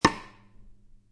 Cheesy Drum Rimshot Cue Botão de Som